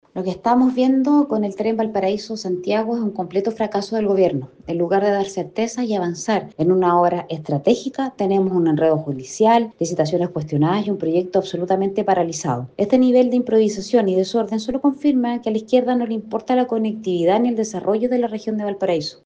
Al respecto, Camila Flores, diputada de Renovación Nacional (RN), criticó esta situación por reflejar una “grave falta de gestión” por parte del Ejecutivo, la que pone en riesgo una obra clave para la región.